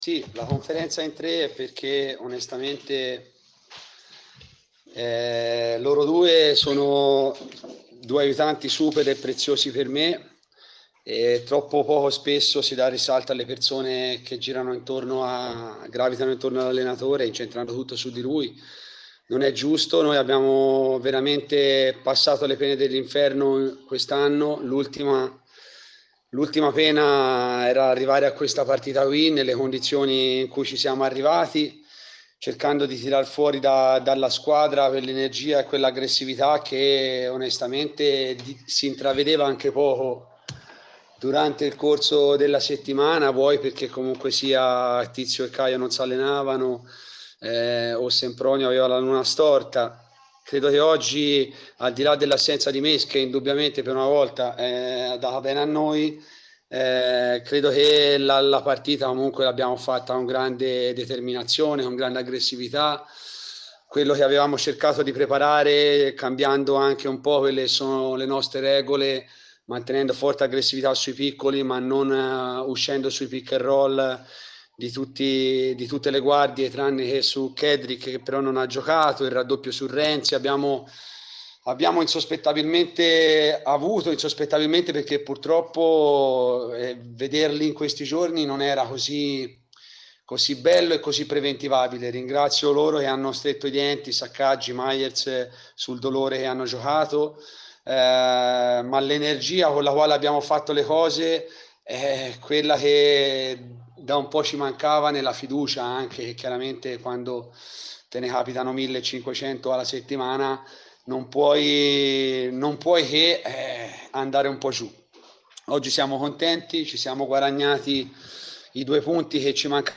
Mens Sana Basket 1871 Siena-Lighthouse Trapani 85-71: conferenza stampa dei tre allenatori biancoverdi - Antenna Radio Esse